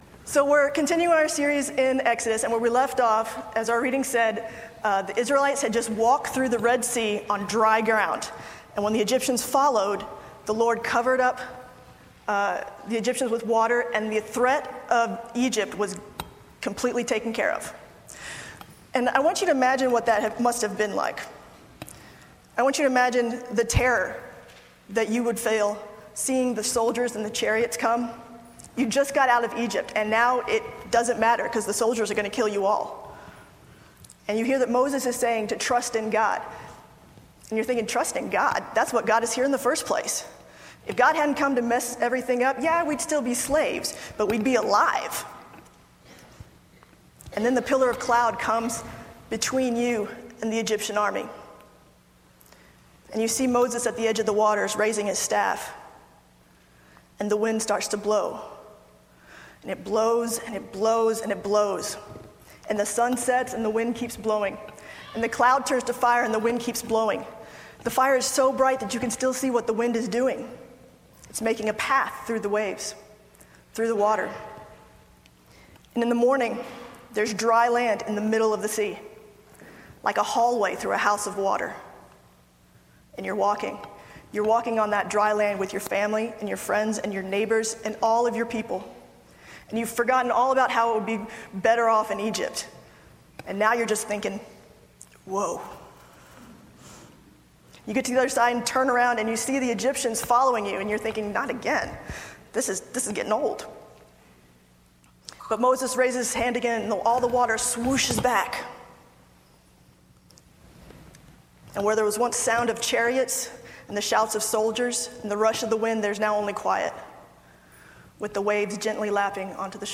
For this sermon, I gave musical intstruments to all of the 3rd – 5th graders in the service and told them to play whenever I said the words “celebrate” or “celebration.”